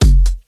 Techno hi hat Free sound effects and audio clips
Fat_Big_Techno_Kick_and_Hat_One_Shot_kWu.wav